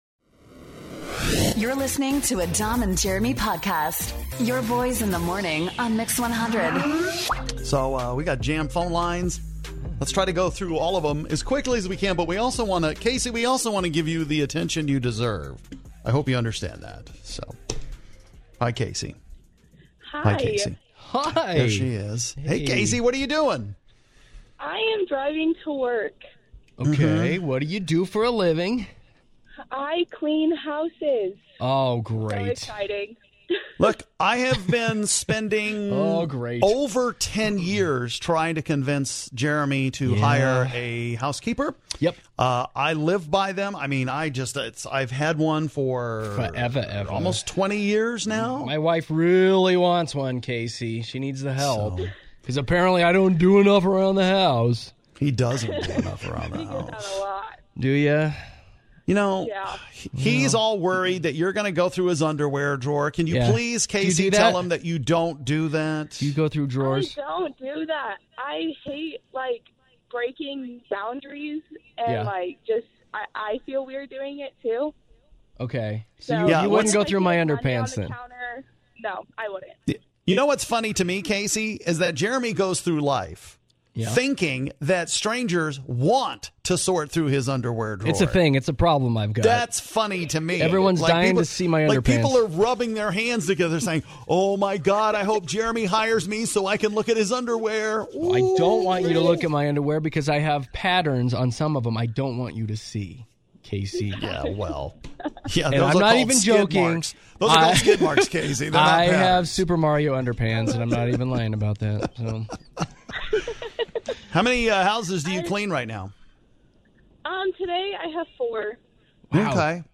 We talk to our LONG time listeners for the first time ever!